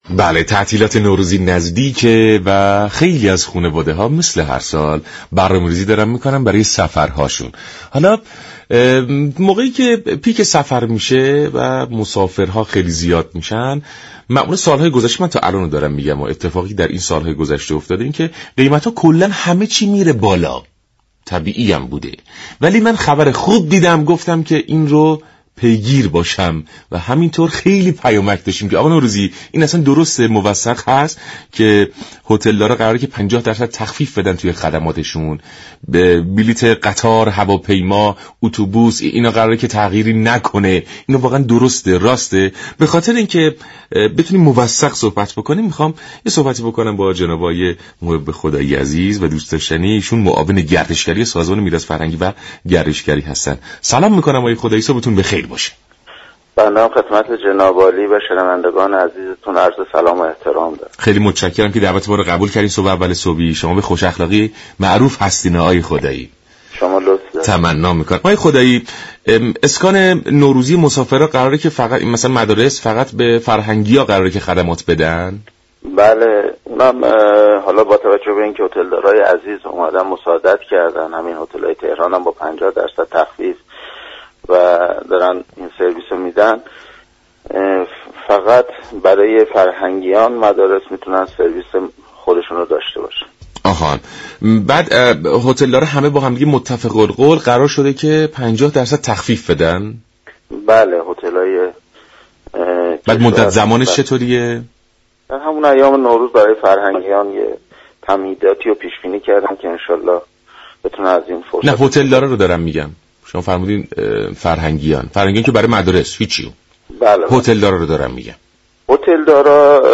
محب خدایی معاون گردشگری سازمان میراث فرهنگی در برنامه «سلام ایران» به ارائه تسهیلات و خدمات ویژه به مسافران نوروزی پرداخت.